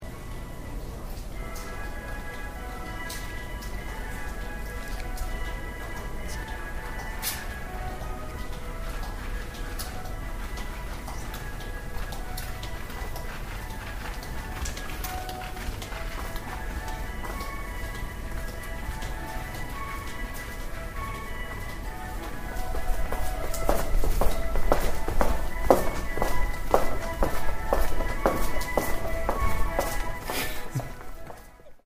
The Four Seasons being broadcast faintly from a home in Venice. September 2013.